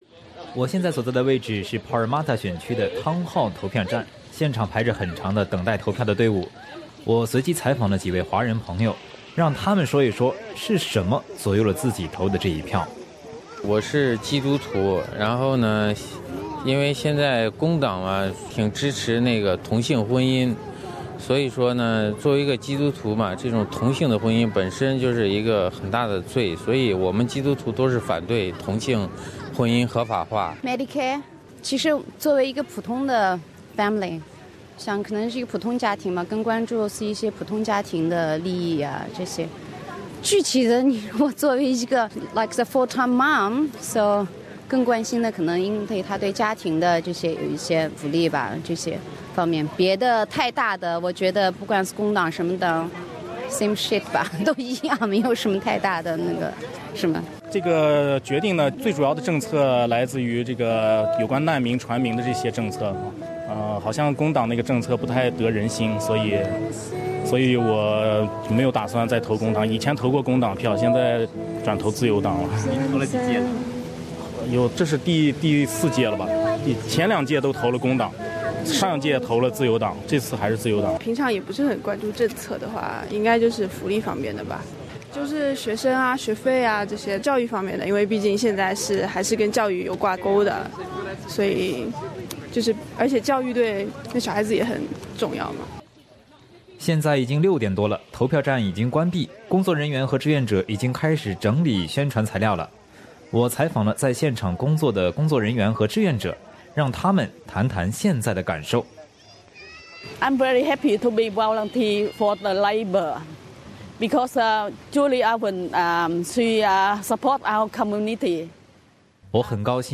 在7月2日联邦大选日，本台记者前往边缘选区Parramatta的Town Hall投票站对华人选民进行了采访，在投票前左右他们的政策是什么呢？投票结束后，我们的记者对投票站工作的志愿者和工作人员也进行了采访，他们有什么感受呢？在采访中，几位华人选民对医疗、教育、同性婚姻、难民等政策很关注，这些政策决定了他们的选票投给谁。
Parramatta Town Hall polling booth (SBS) Source: SBS